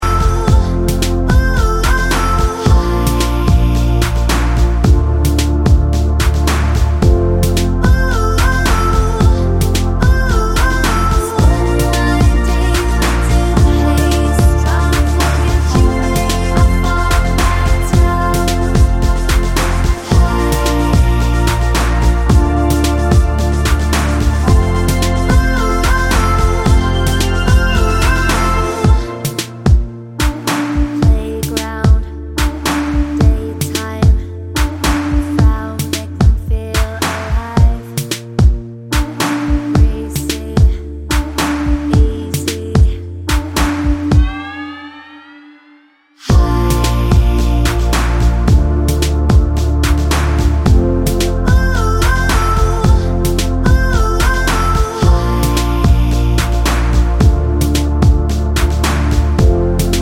No Ohs Pop (2010s) 3:28 Buy £1.50